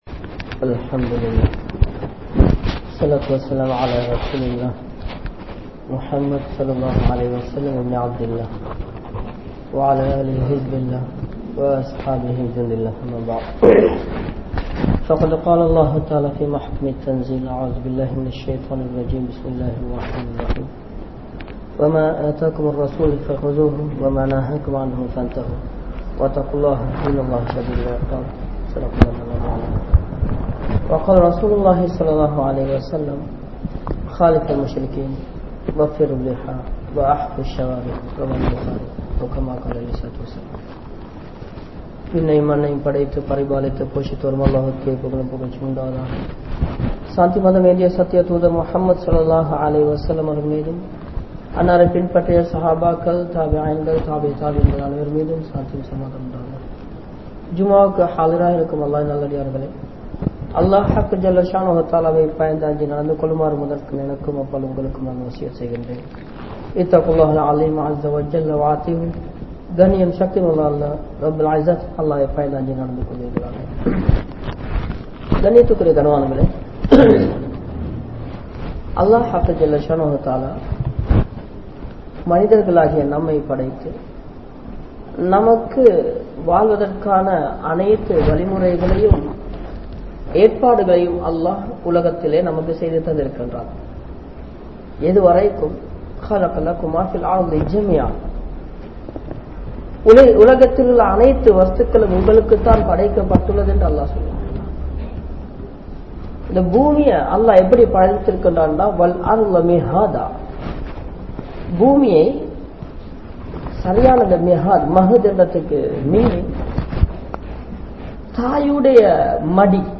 Sariyathin Paarvaiel Dhaadi (ஷரிஅத்தின் பார்வையில் தாடி) | Audio Bayans | All Ceylon Muslim Youth Community | Addalaichenai